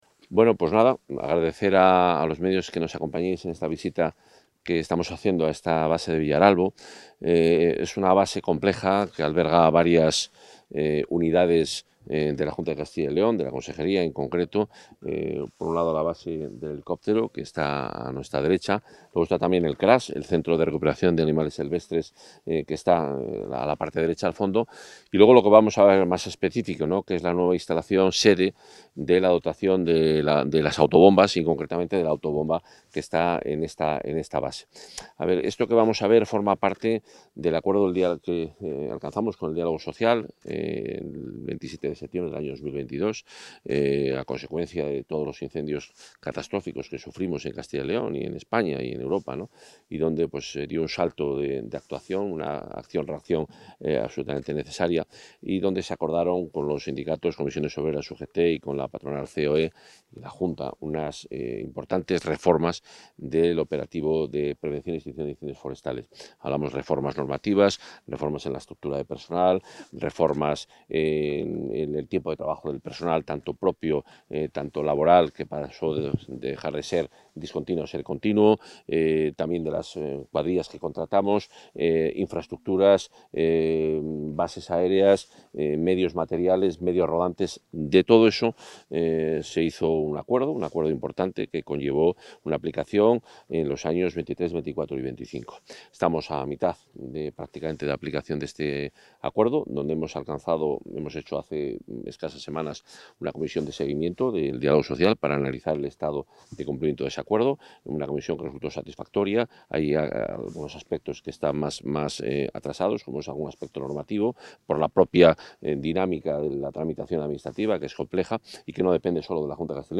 Intervención del portavoz.